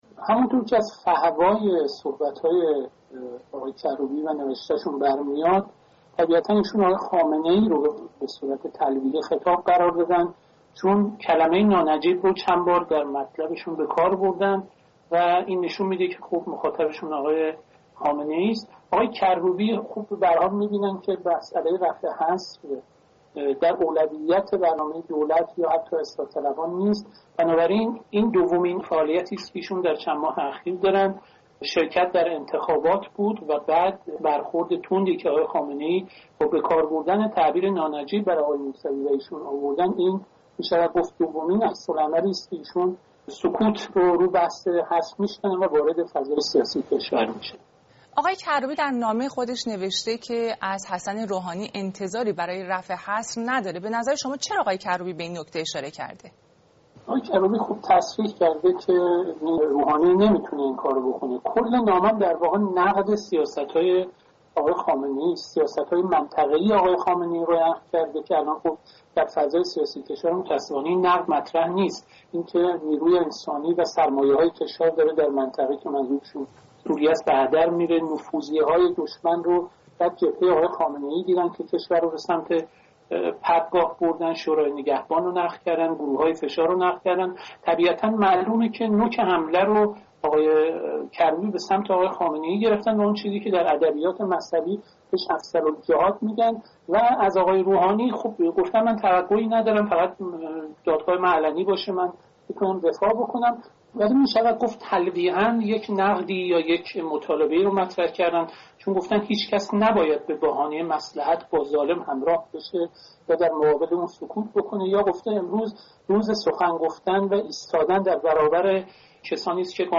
گفت‌وگوهایی درباره نامه تازه کروبی به روحانی